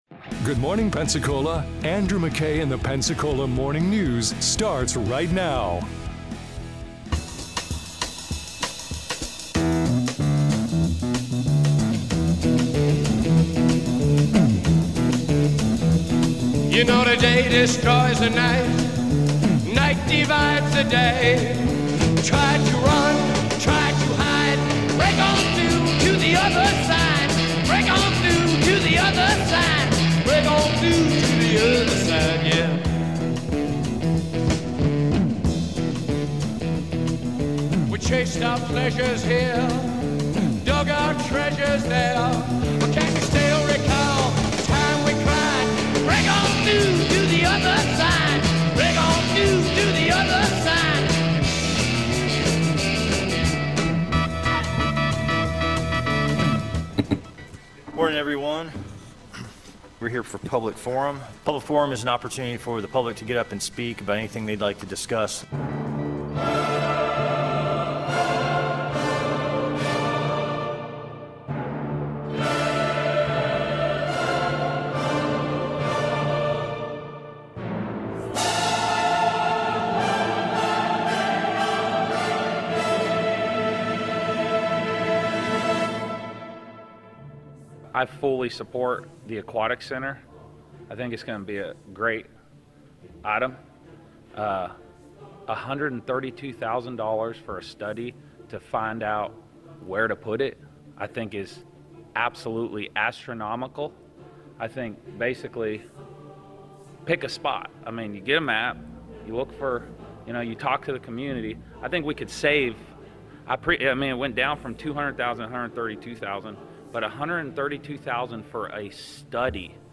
Study for the Santa Rosa County Aquatic Center / Replay of interview with DC Reeves